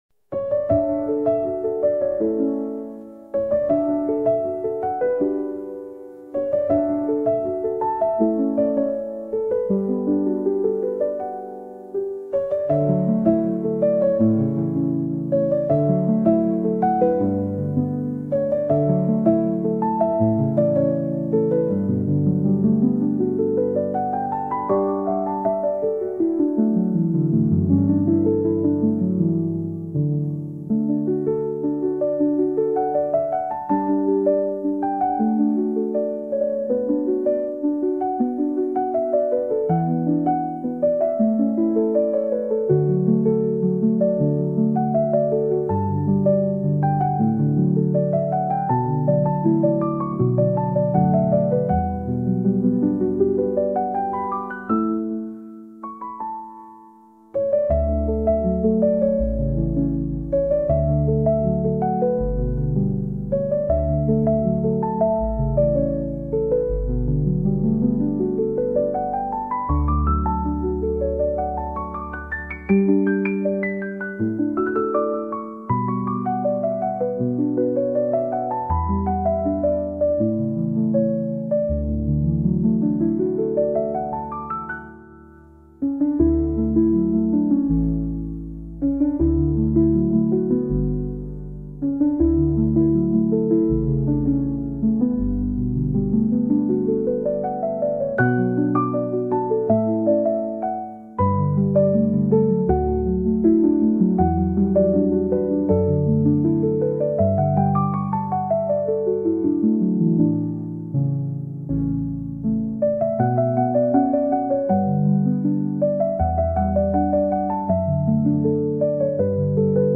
gentle and contemplative piano piece
minimalist piano music